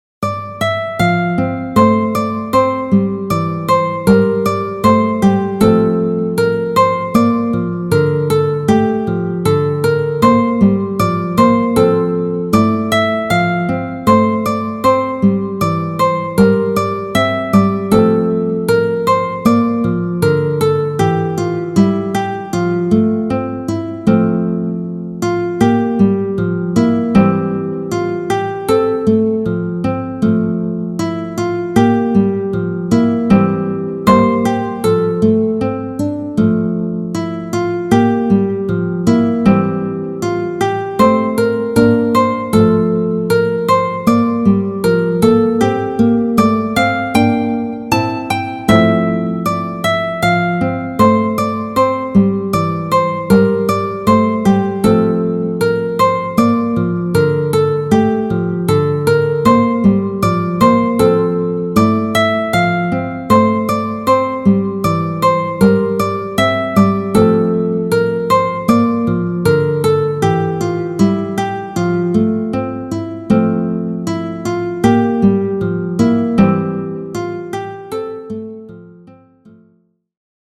フリーBGM 街・拠点・村など のんびり・ほのぼの
フェードアウト版のmp3を、こちらのページにて無料で配布しています。